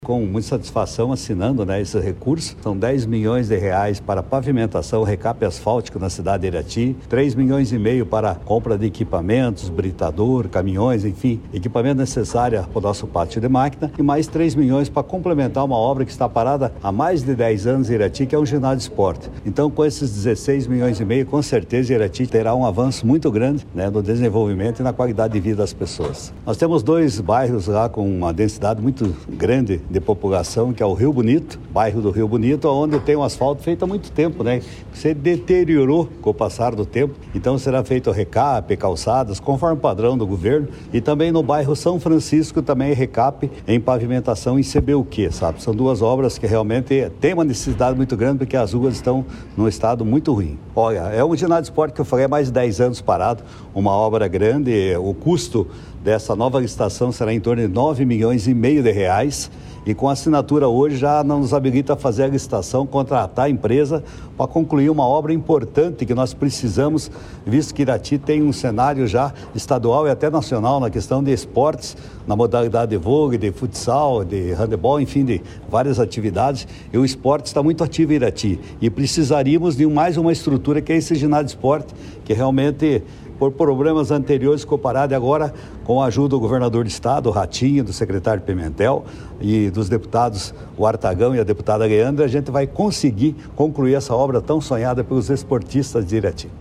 Sonora do prefeito de Irati, Jorge Derbli, sobre o repasse de R$ 16,5 milhões para pavimentação e conclusão de ginásio em Irati